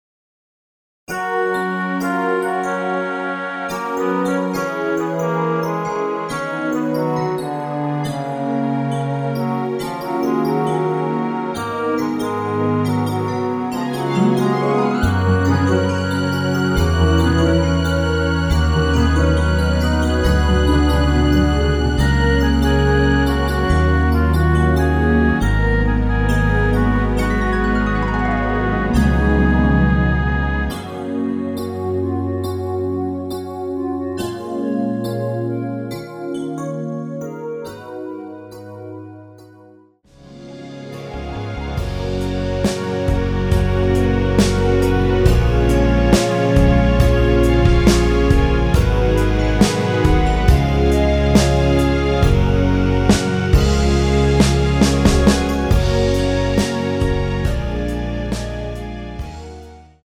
원키에서(-1)내린 멜로디 포함된 MR입니다.(미리듣기 확인)
F#
노래방에서 노래를 부르실때 노래 부분에 가이드 멜로디가 따라 나와서
앞부분30초, 뒷부분30초씩 편집해서 올려 드리고 있습니다.